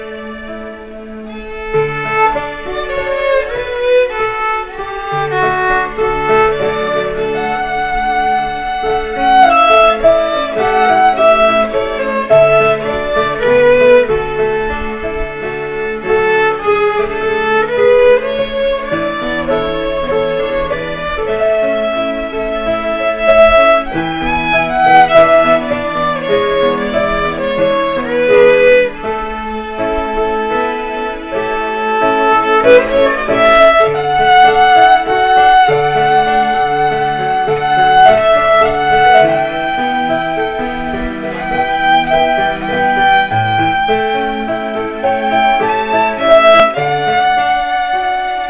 Violin - 1989 & 2002
(Jangan katawa.. ni musik prektis ni... banyak sumbang, banyak pasir-pasir dan tu gogor di buat² (blum natural)).
violin02.wav